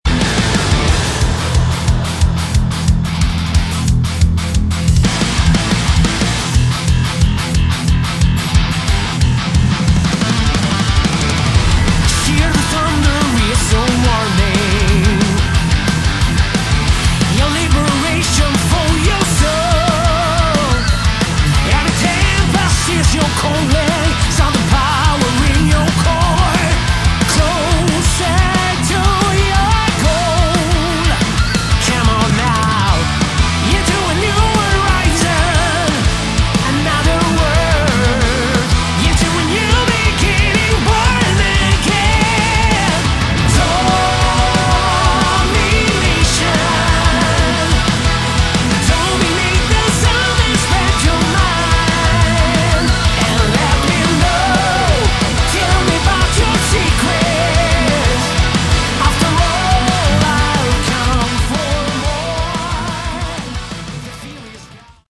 Category: Melodic Metal / Prog Metal
vocals
guitars
bass
drums
keyboards